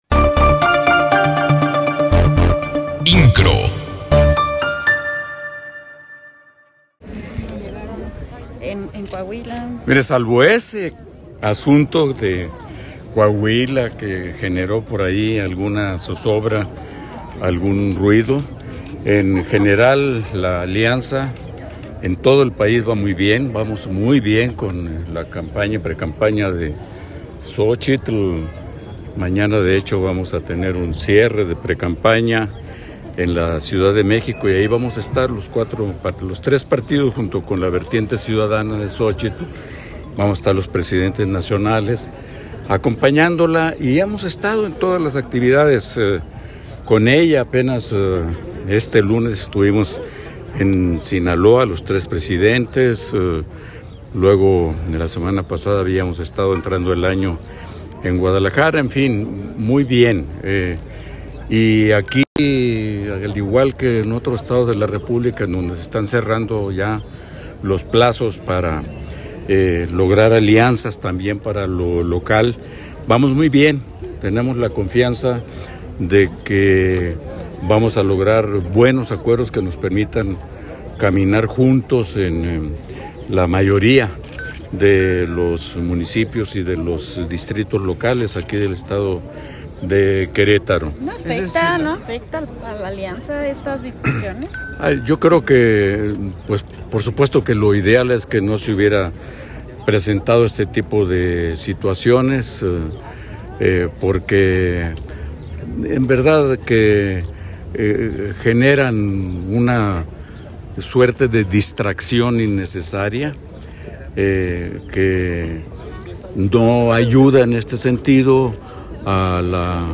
Entrevistado a su llegada al informe legislativo de Felifer Macías en el Auditorio Josefa Ortiz, el dirigente nacional del PRD Jesús Zambrano señala que no hay fracturas.